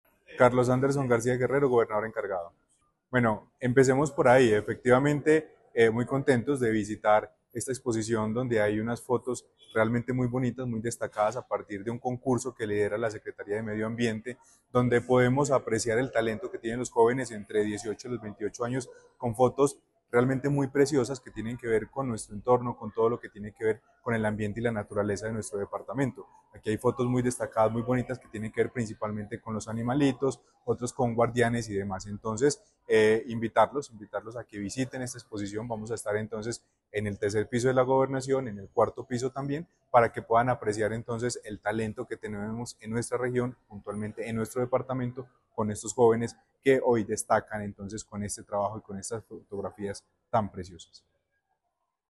Carlos Anderson García Guerrero, gobernador (e) de Caldas